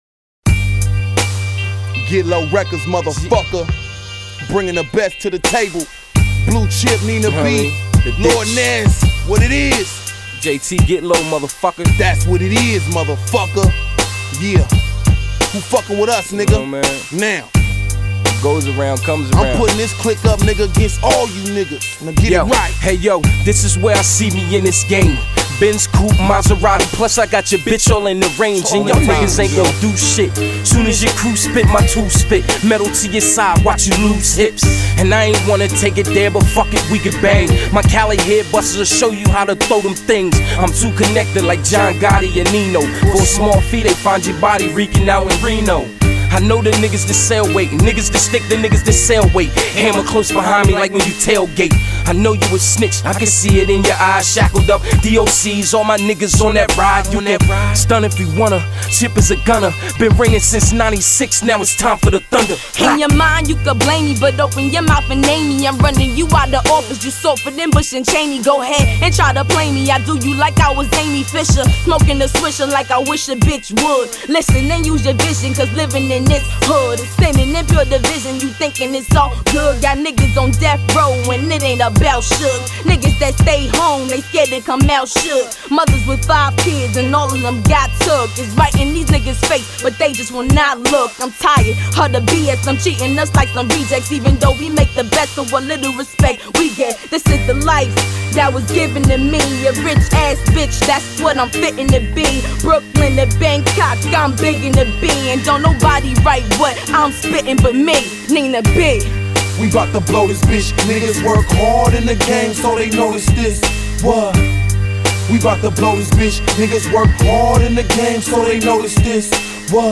Назад в (rap)...
Файл в обменнике2 Myзыкa->Рэп и RnВ